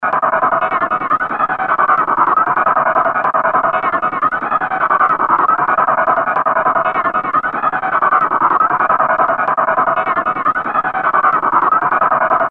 Stutter Stop.wav